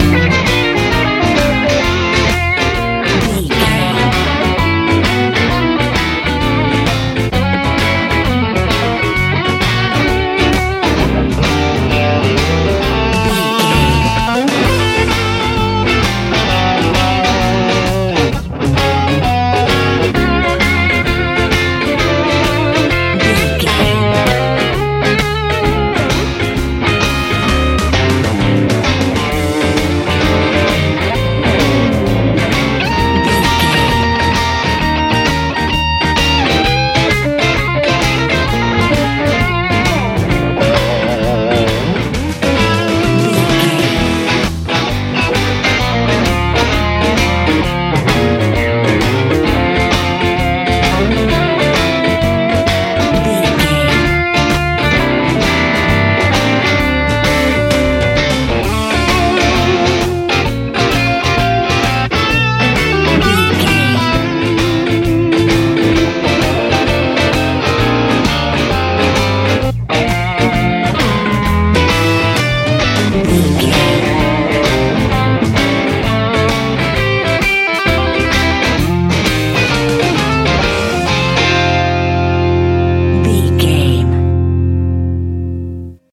country rock feel
Ionian/Major
driving
energetic
electric guitar
bass guitar
electric piano